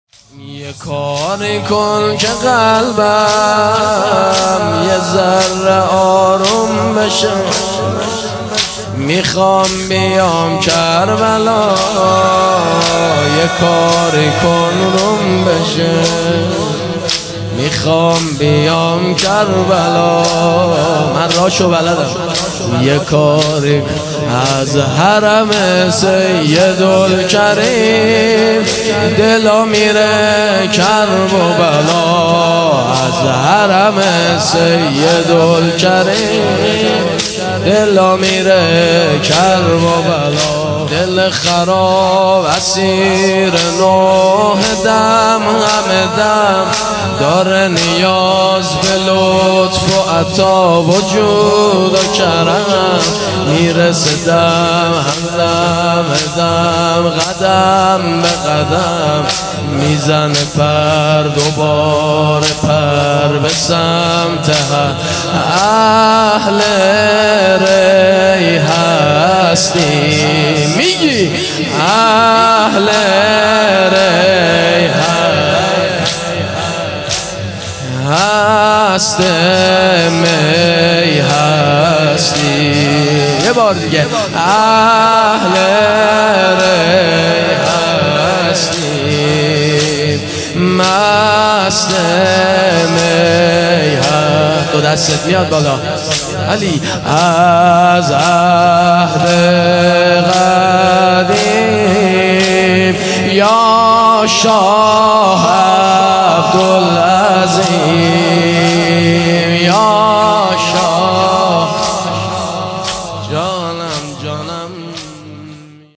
جلسه هفتگی هیئت جنت العباس (ع) کاشان